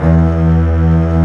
Index of /90_sSampleCDs/Roland - String Master Series/CMB_Combos 2/CMB_Full Section
STR STRING02.wav